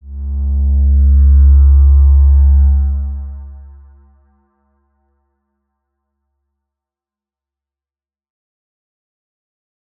X_Windwistle-C#1-pp.wav